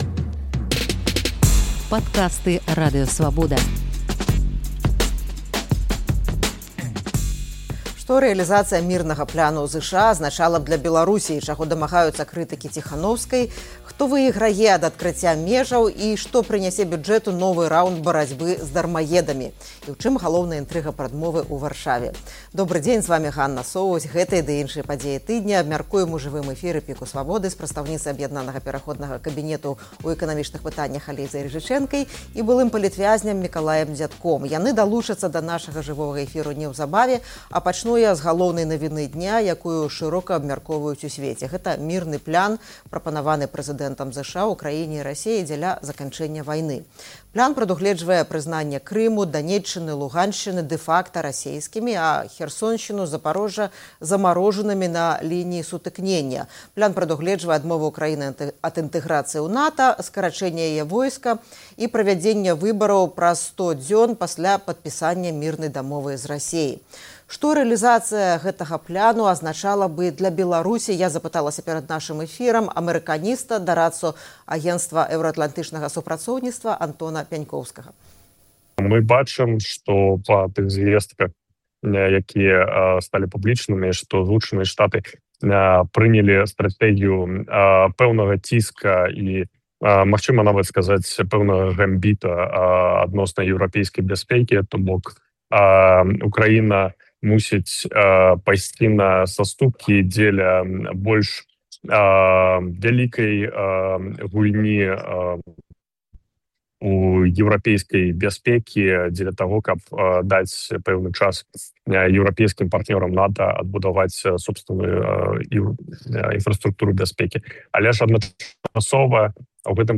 Гэтыя ды іншыя падзеі тыдня абмяркоўваем у жывым эфіры «ПіКу Свабоды»